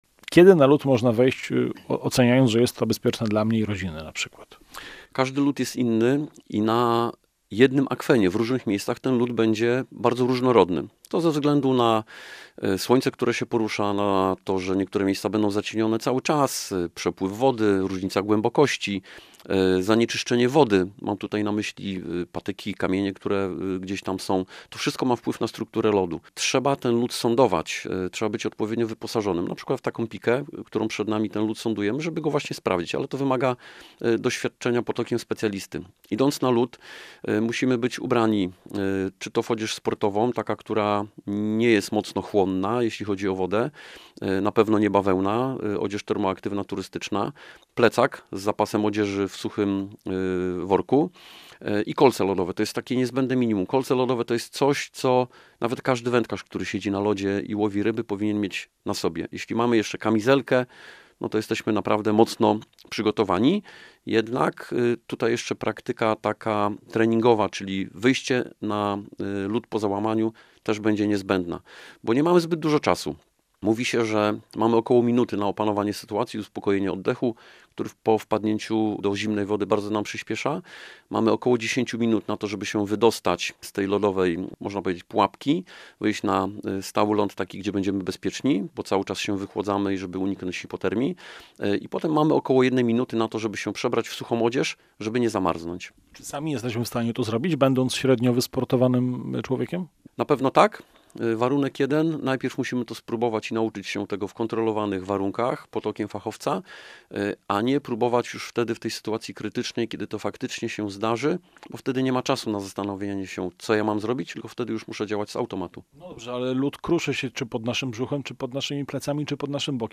Posłuchaj rozmowy z st. kpt.